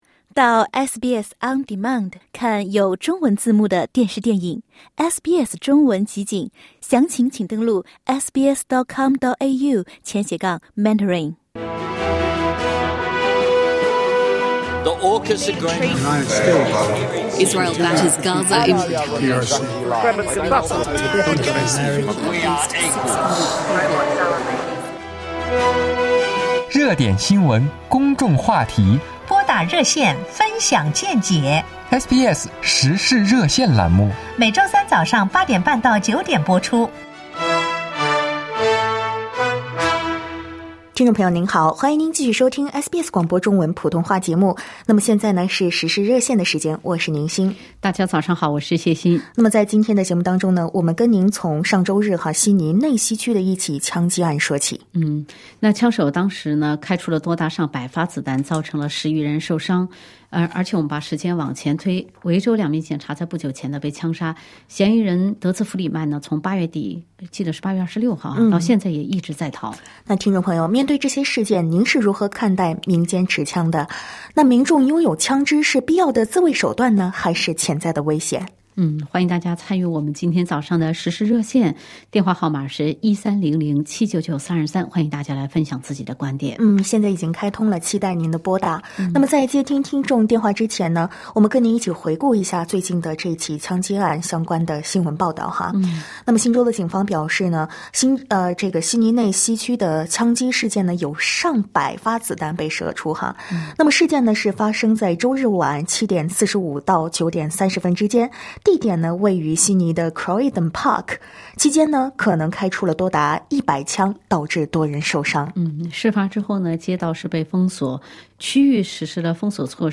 在本期《时事热线》节目中，听友们就澳洲枪支法规分享了各自的看法。